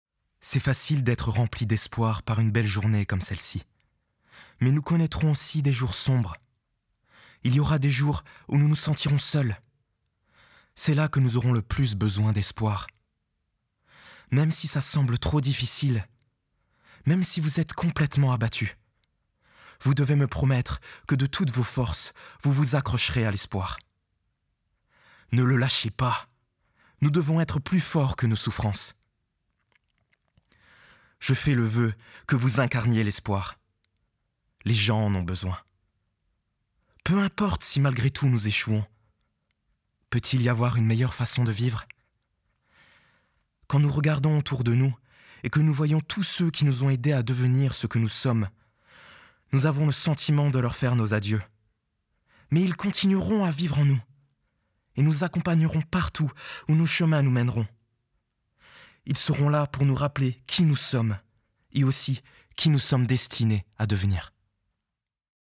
Discours
24 - 41 ans - Ténor